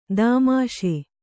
♪ dāmāṣe